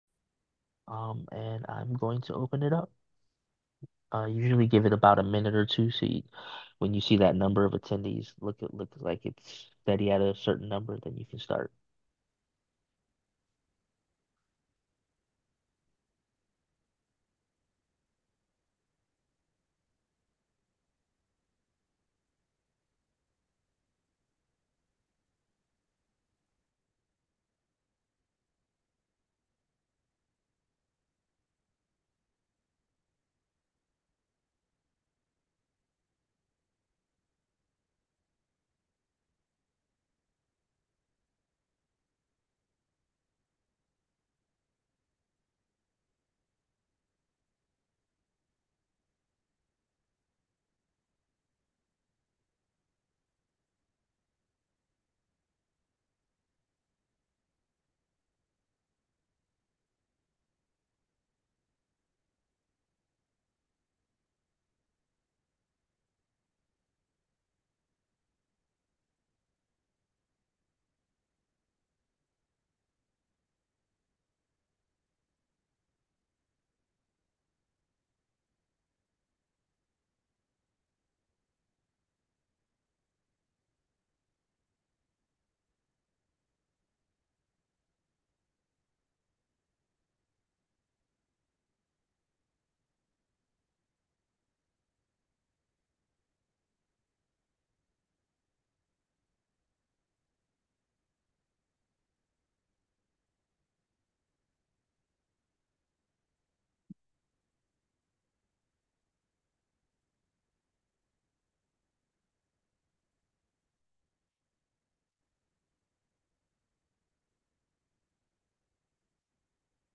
CyberTraining (NSF-23-520) Program Webinar